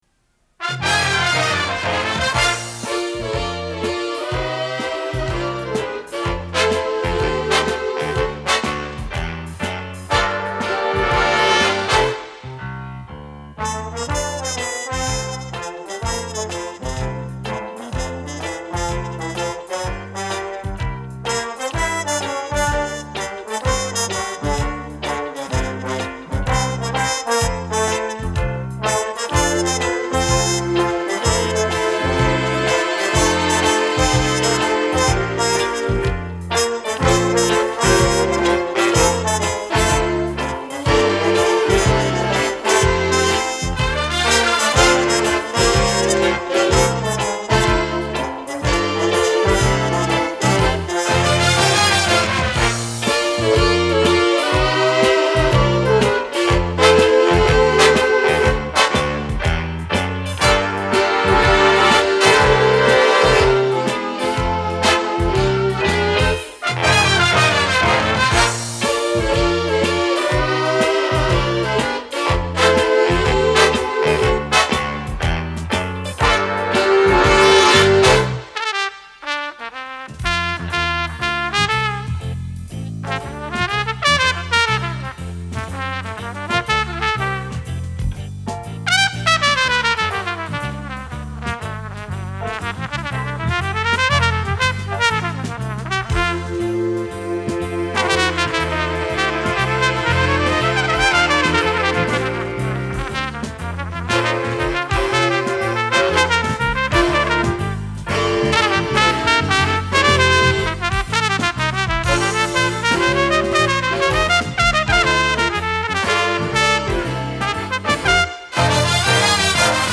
Инструментальная музыка в исполнении оркестров и ансамблей стран народной демократии.